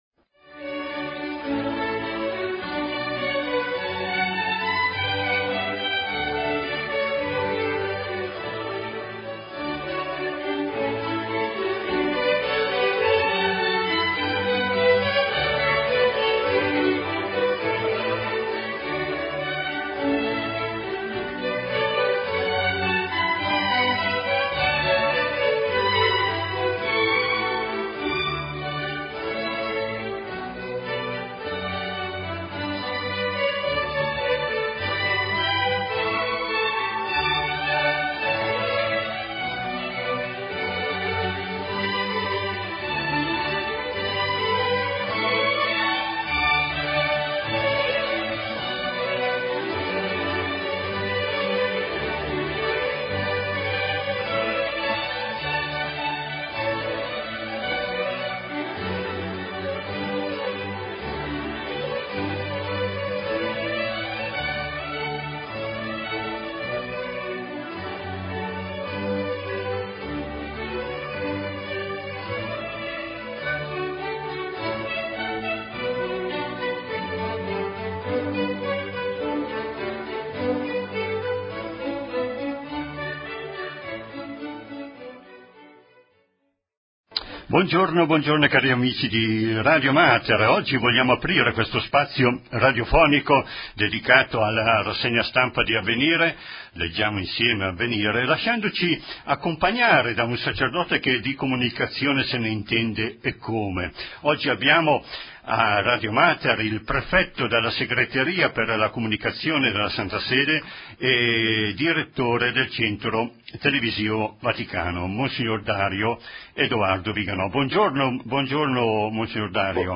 Registrazione Degli Esercizi Spirituali